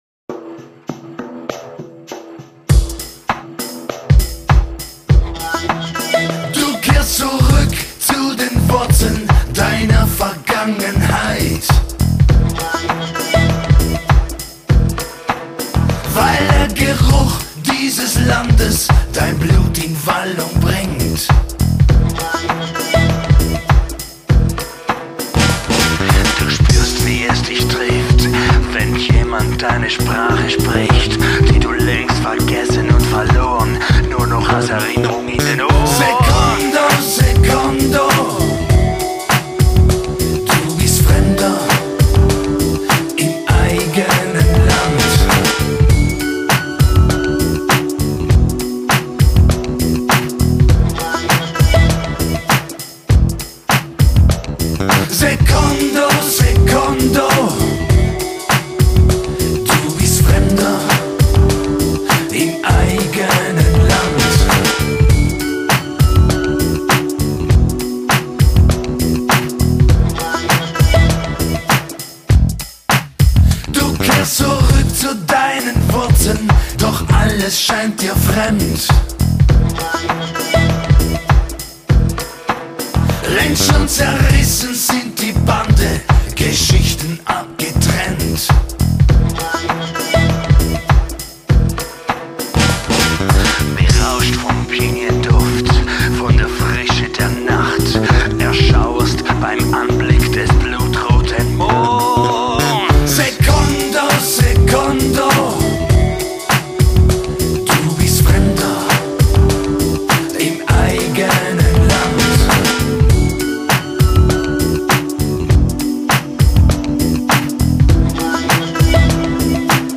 Vocals, Gitarre
Keyboards, Handorgel
Loops, Beats, Samples